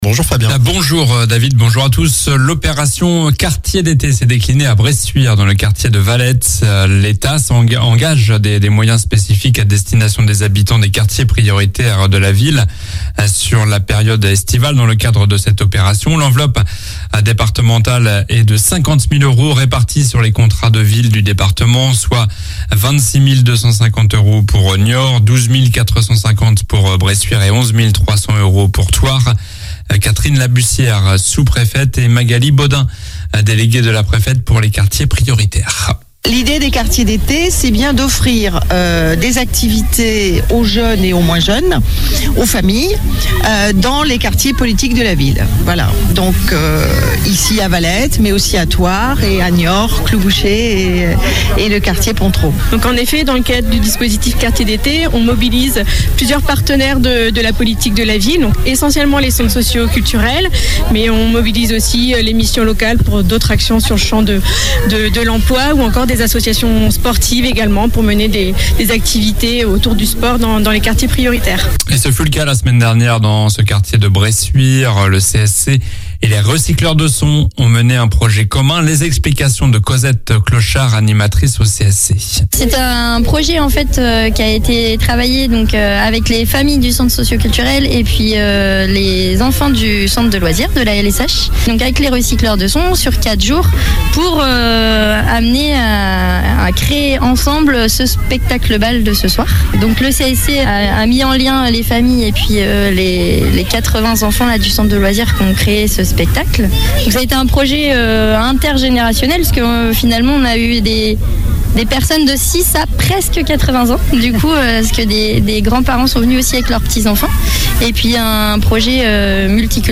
Journal du mardi 25 juillet (matin)